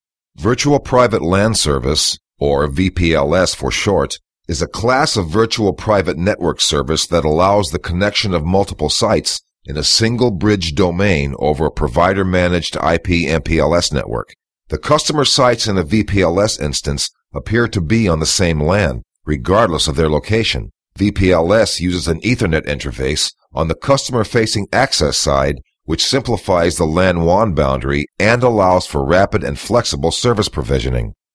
Authoritative, edgy, compelling, provocative, confident, informative, storyteller, believable, real.
englisch (us)
mid-atlantic
middle west
Sprechprobe: eLearning (Muttersprache):